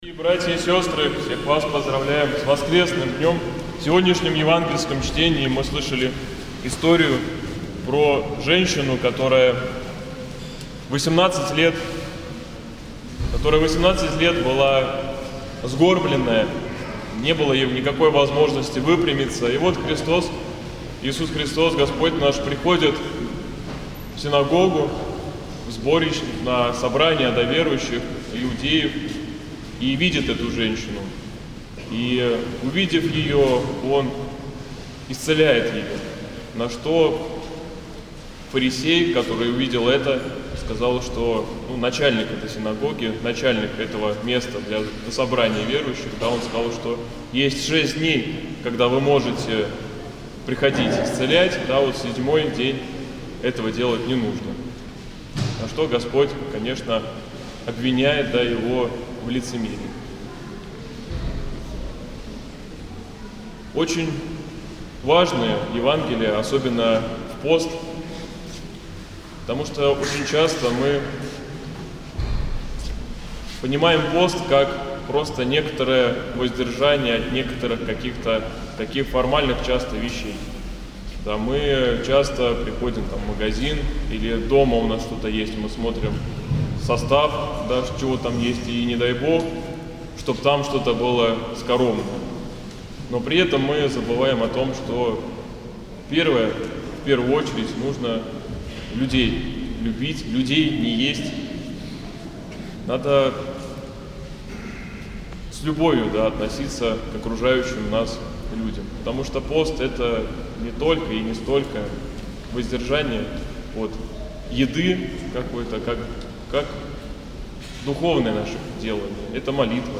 ранняя Литургия